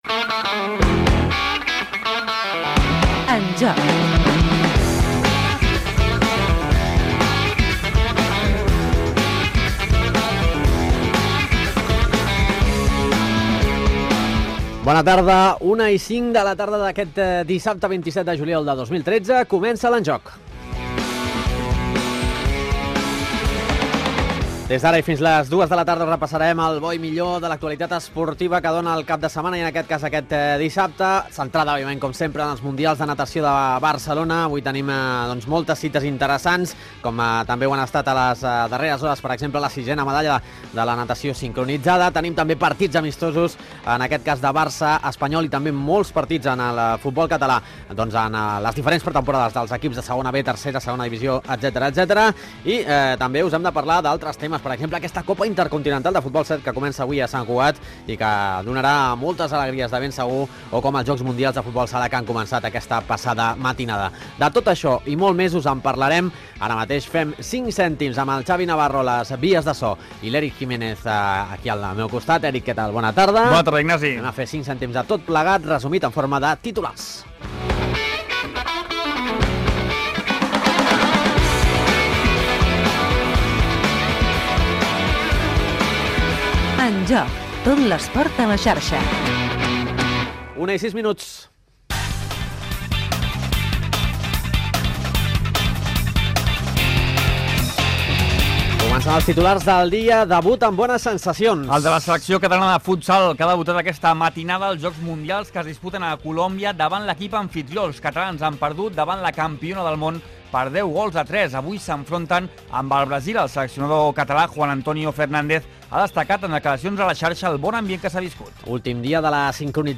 Careta del programa, data, hora, sumari, indicatiu i titulars
Esportiu